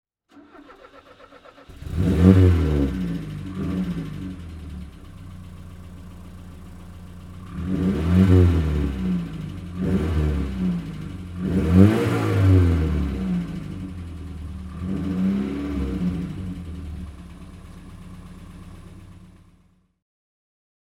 Audi (1965) - Starten und Leerlauf